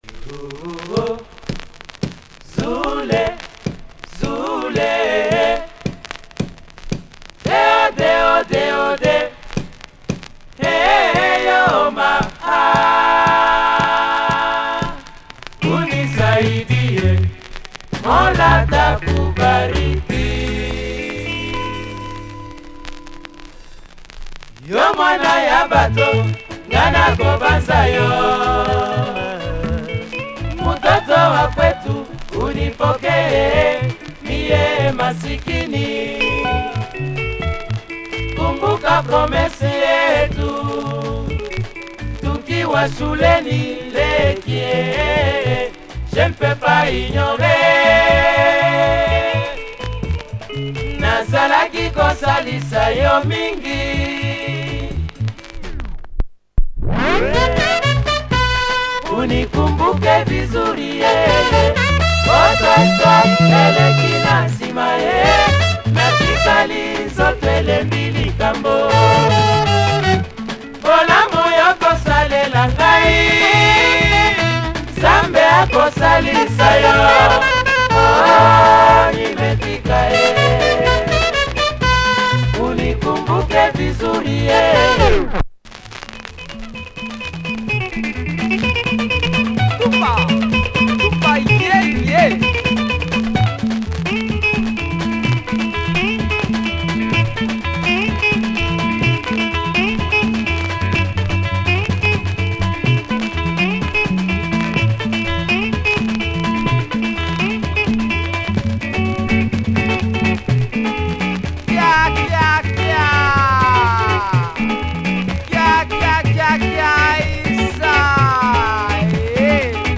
Nice Lingala! Horns, great guitar work.